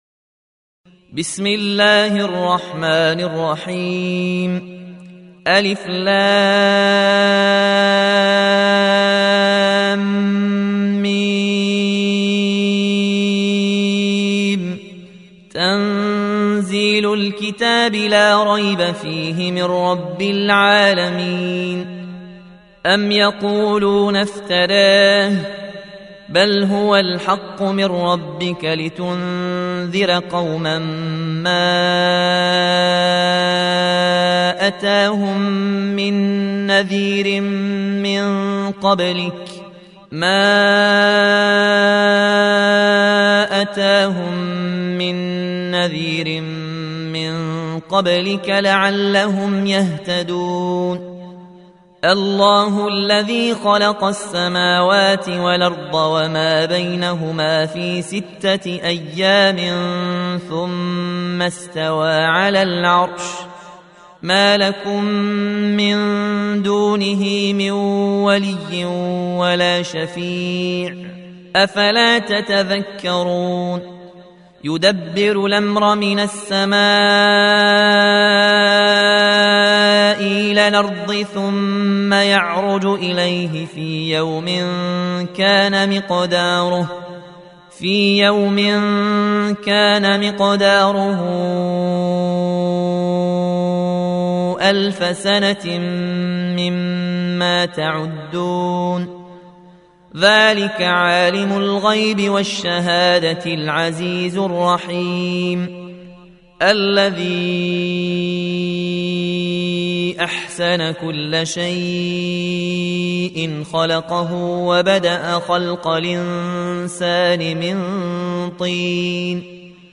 Surah Sequence تتابع السورة Download Surah حمّل السورة Reciting Murattalah Audio for 32. Surah As�Sajdah سورة السجدة N.B *Surah Includes Al-Basmalah Reciters Sequents تتابع التلاوات Reciters Repeats تكرار التلاوات